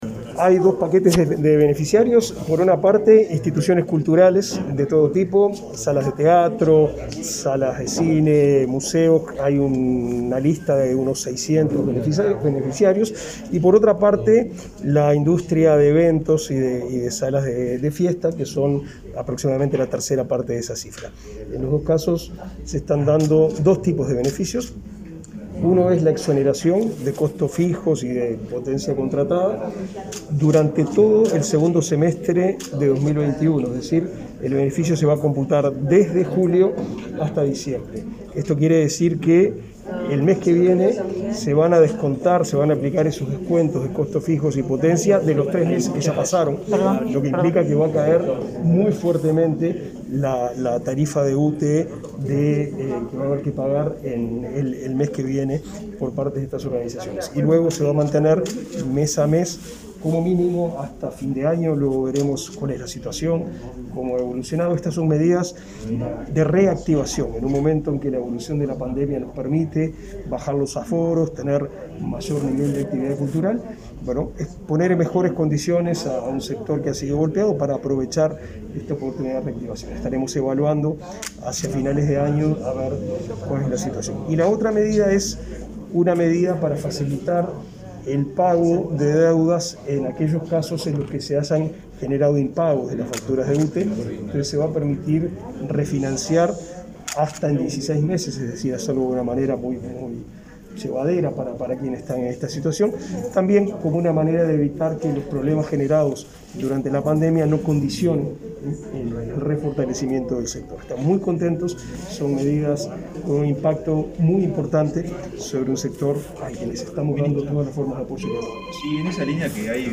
Declaraciones a la prensa del ministro de Educación y Cultura, Pablo da Silveira